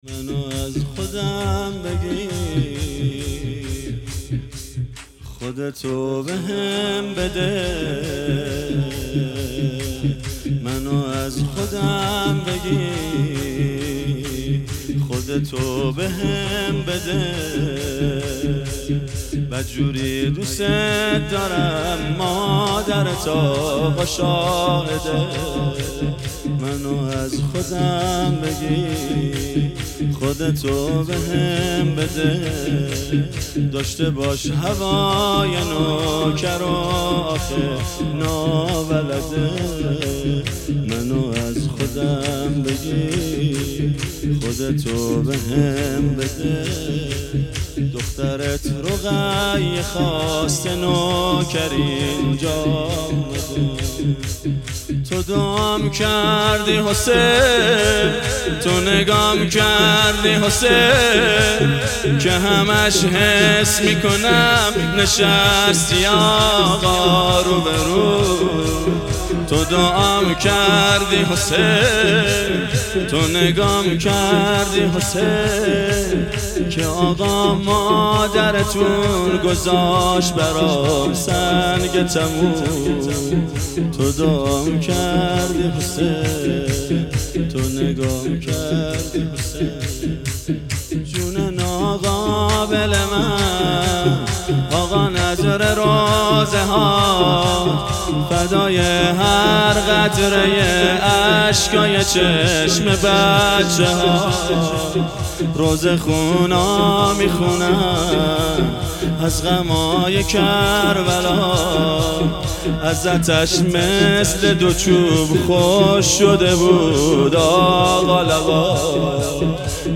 شور
شهادت حضرت زهرا 1443 (ه ق)